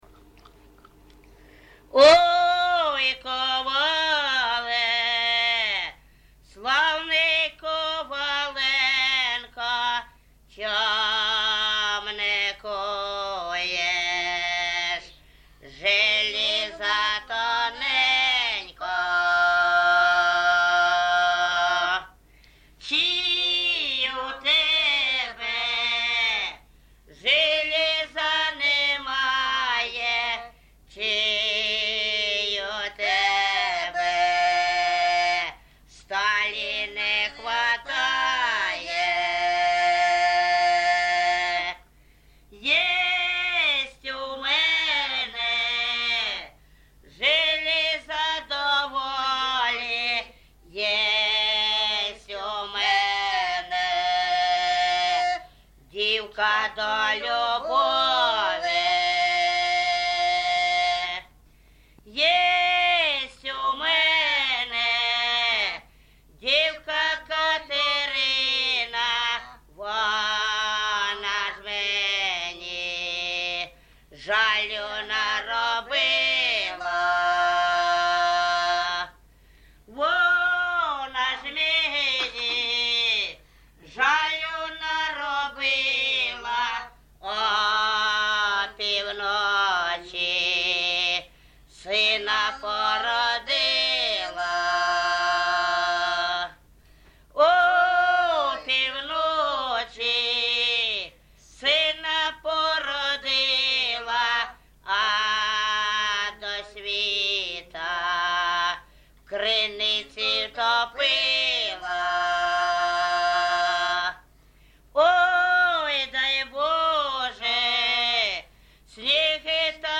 ЖанрПісні з особистого та родинного життя, Балади
Місце записус. Гарбузівка, Сумський район, Сумська обл., Україна, Слобожанщина